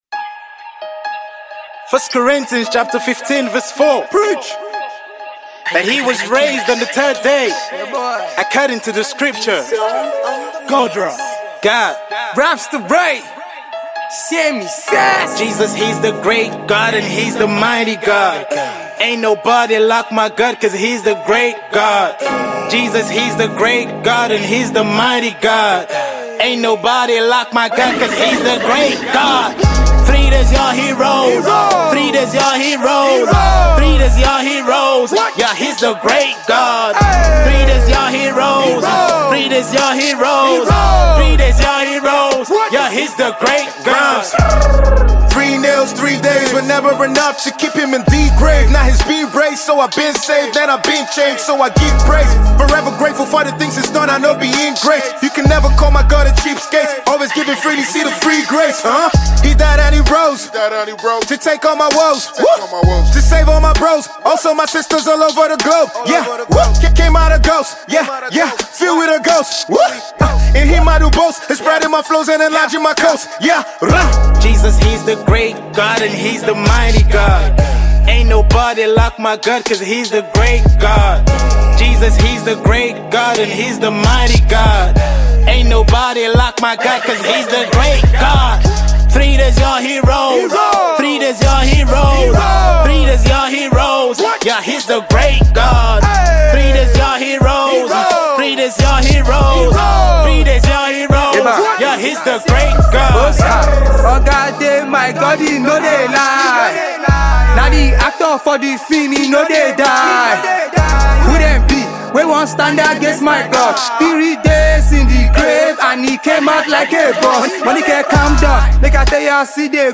Abuja based rap artiste